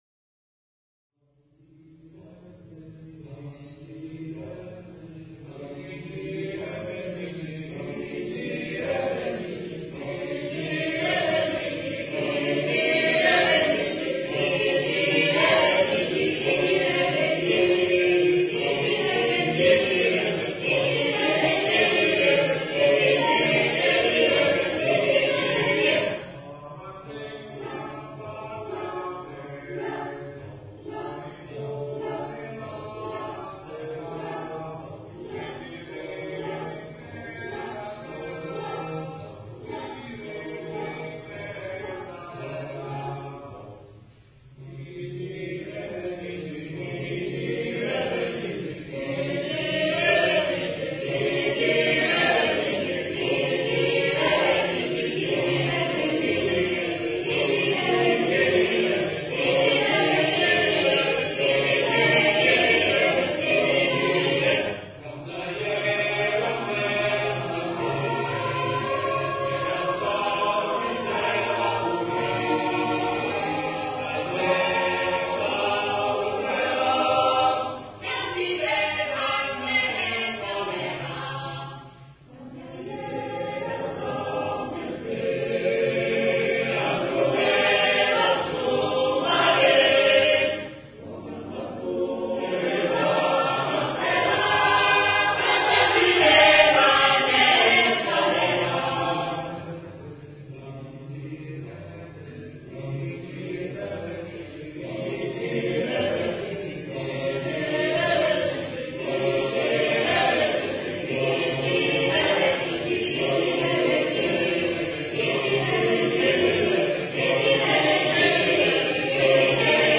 Nell'aia - Coro Stelutis
[ voci miste ]